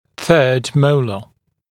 [θɜːd ‘məulə][сё:д ‘моулэ]третий моляр